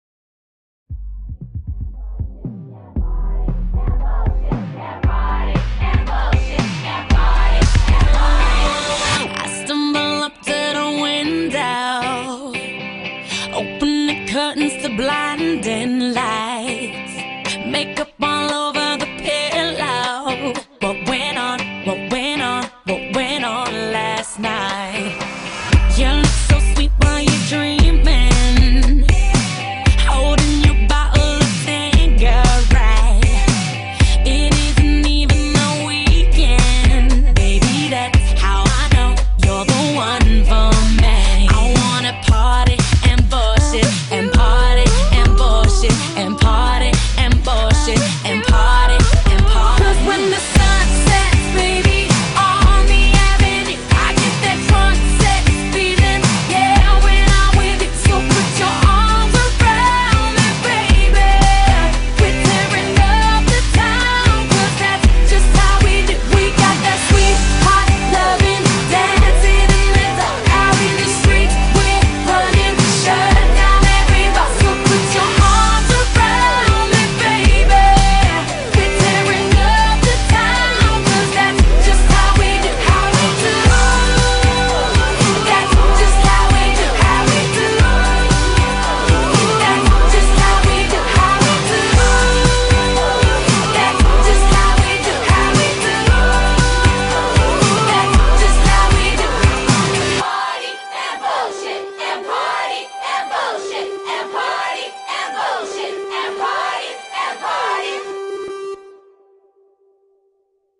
BPM116
Audio QualityCut From Video